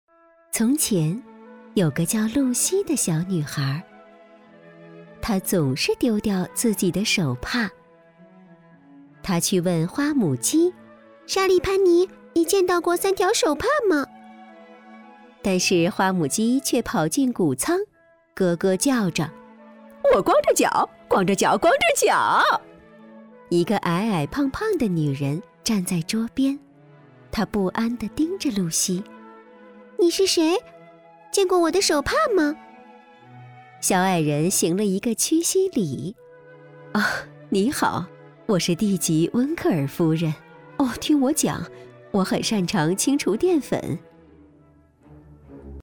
女国语330